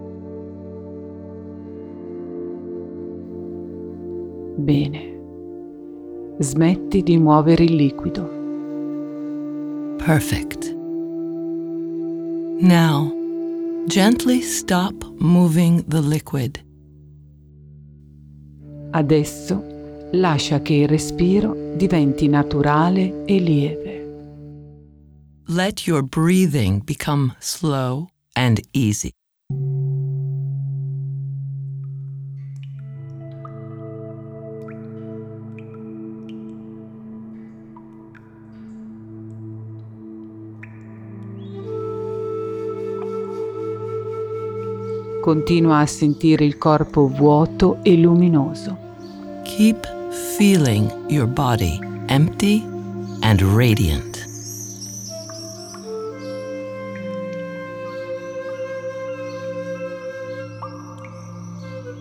It is a very gentle and calm process, with ample spaces to immerse oneself in the sounds of nature, between one explanation and another.
Water-meditation-Two-voices-3.m4a